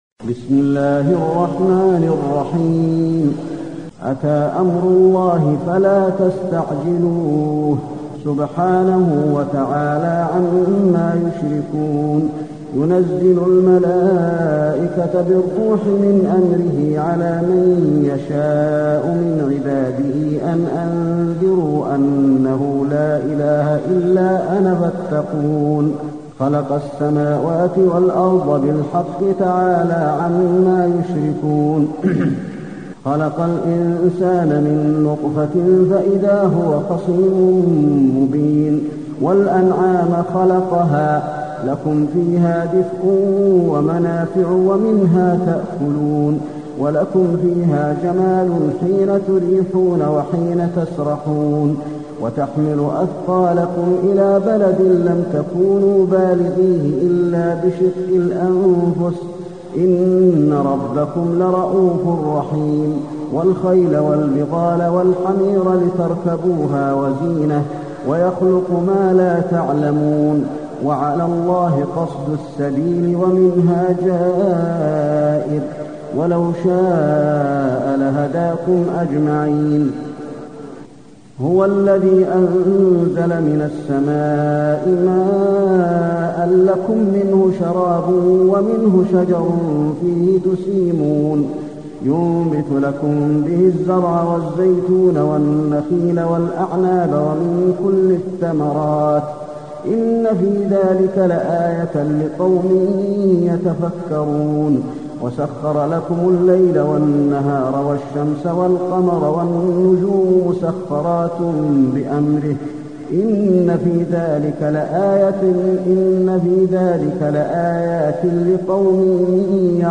المكان: المسجد النبوي النحل